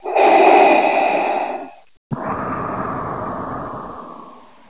datthbreath.wav